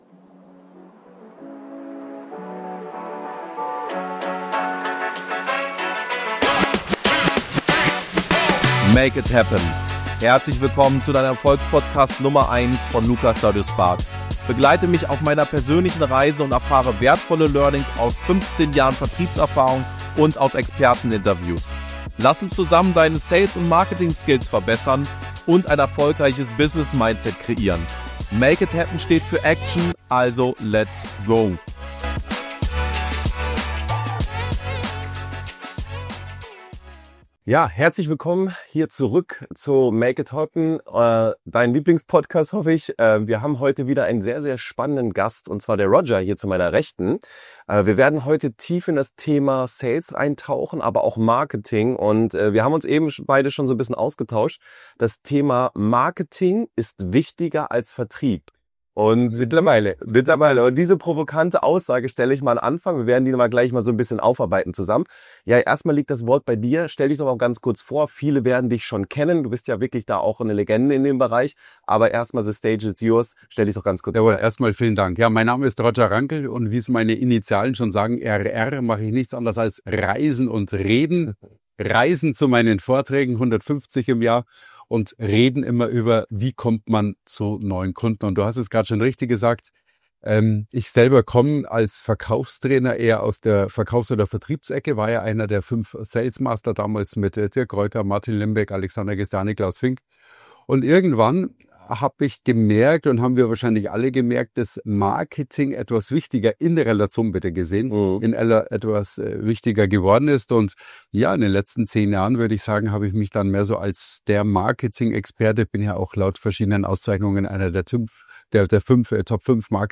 So gewinnst du JEDEN Kunden! | Exklusives Interview